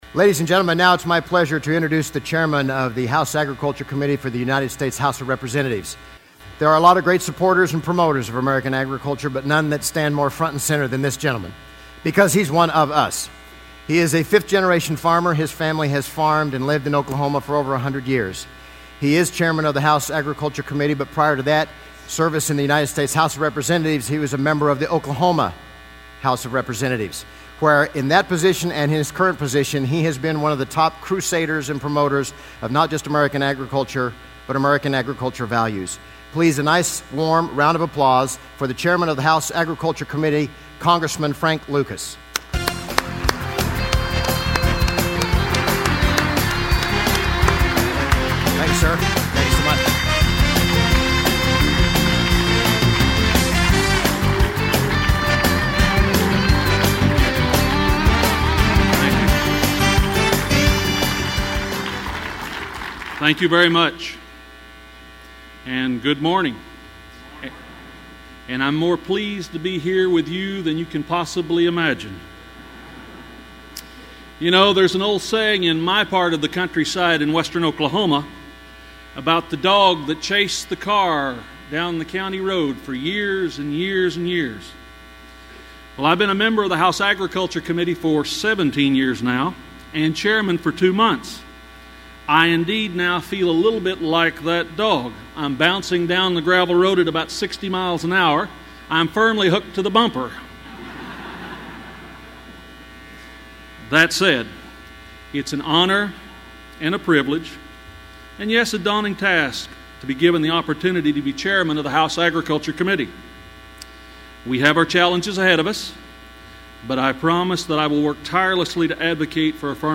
The chairman of the House Agriculture Committee discussed government over-regulation and prospects for the 2012 Farm Bill during the general session at Commodity Classic 2011.
cc11-lucas-speech.mp3